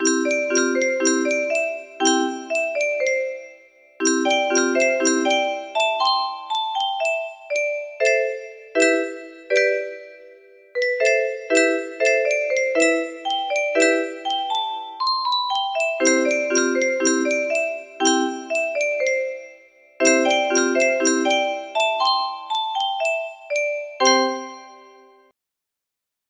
Spritely music box melody